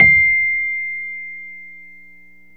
RHODES-C6.wav